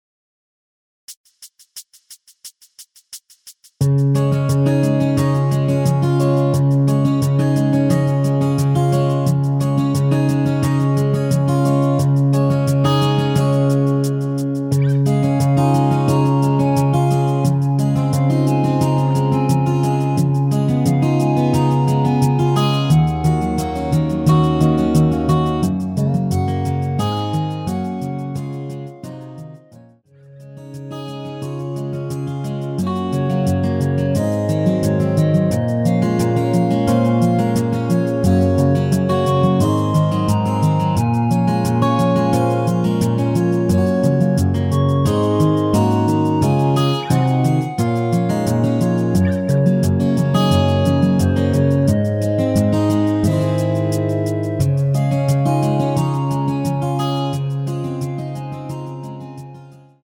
원키에서(-1)내린 멜로디 포함된 MR입니다.(미리듣기참조)
Db
◈ 곡명 옆 (-1)은 반음 내림, (+1)은 반음 올림 입니다.
앞부분30초, 뒷부분30초씩 편집해서 올려 드리고 있습니다.
중간에 음이 끈어지고 다시 나오는 이유는